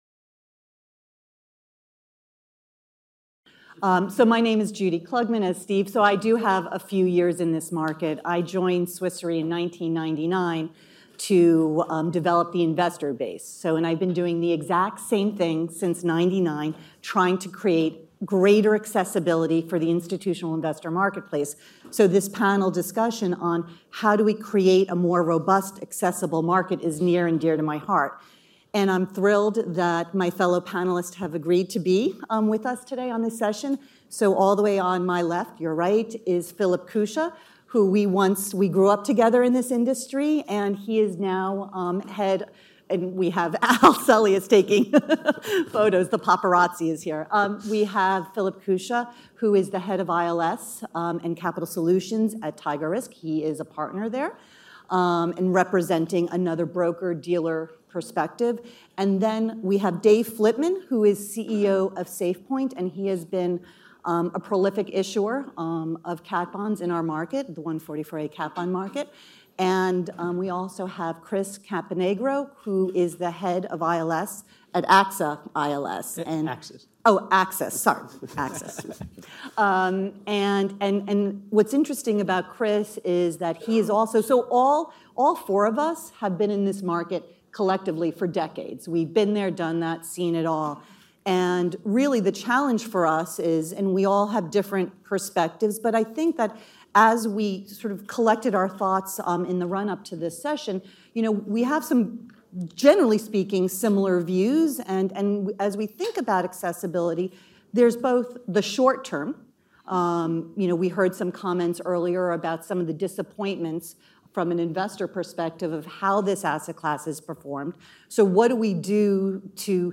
Our second session at the Artemis ILS NYC 2022 conference, held in April in New York, saw insurance-linked securities (ILS) market experts discussing the need to keep the asset classes protection accessible to as wider range of cedents as possible.